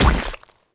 etfx_explosion_slime.wav